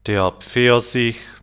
Broskev (627x640)broskev der Pfirsich [pfirzich]
der-Pfirsich.wav